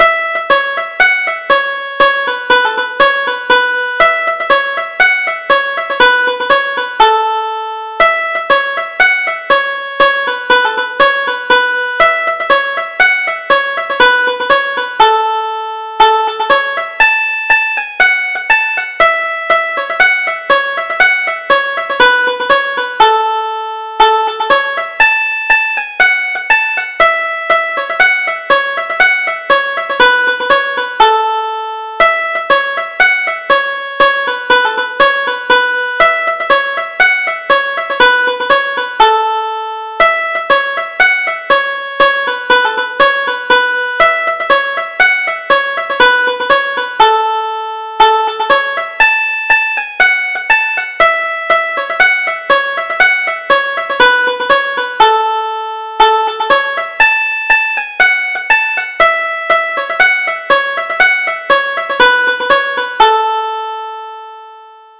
Irish Traditional Polkas